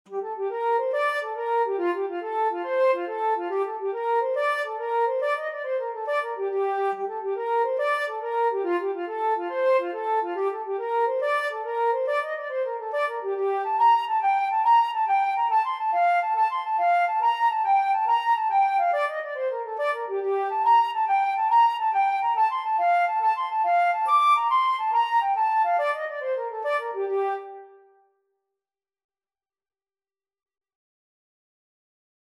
6/8 (View more 6/8 Music)
F5-D7
Flute  (View more Intermediate Flute Music)
Traditional (View more Traditional Flute Music)